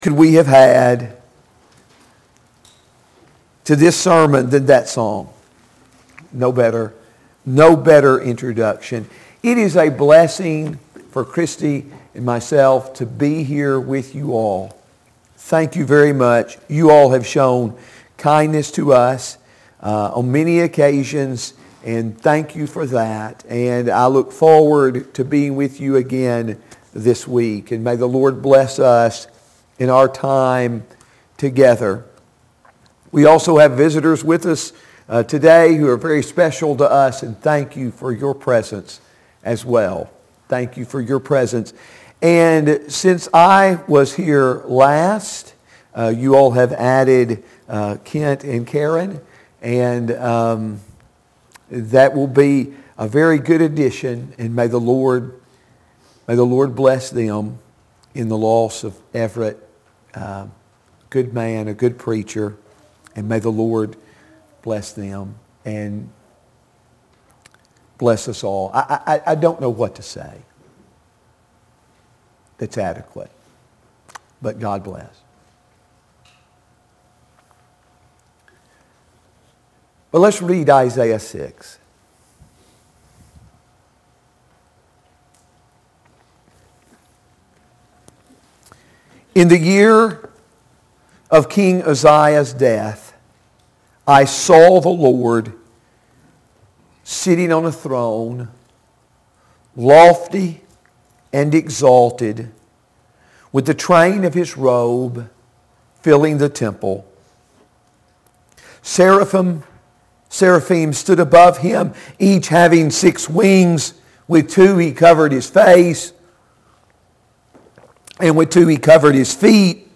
Sunday AM Worship